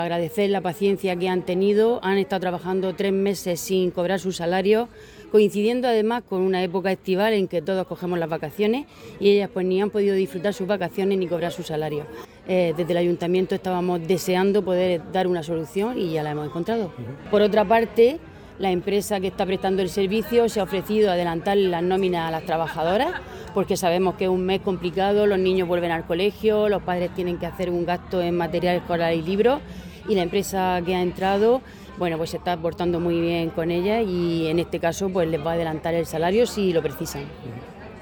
Declaraciones de la alcaldesa de Alcantarilla, Paqui Terol.